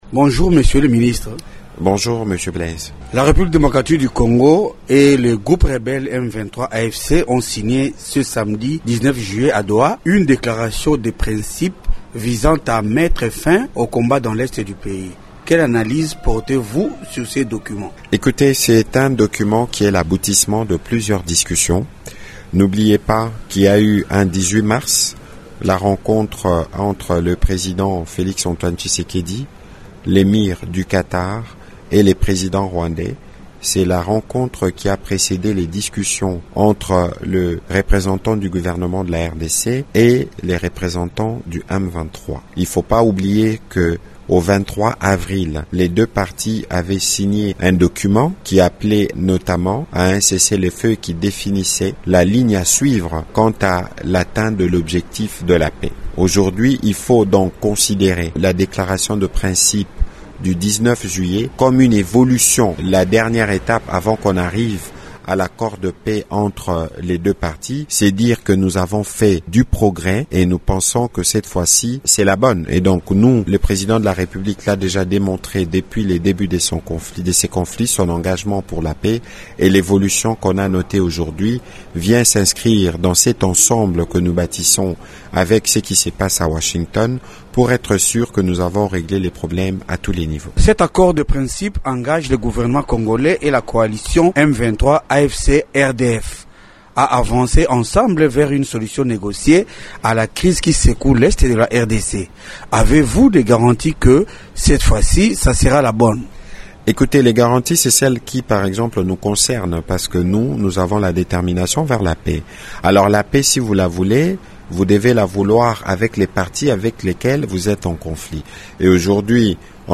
Invité de Radio Okapi, il considère ce document comme une avancée significative, et une étape cruciale menant vers un futur accord de paix entre Kinshasa et Kigali.